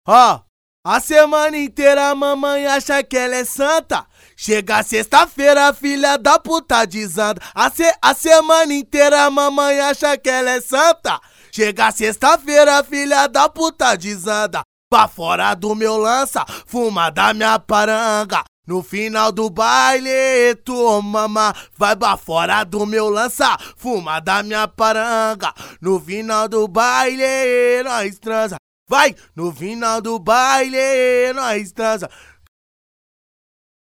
Acapellas de Funk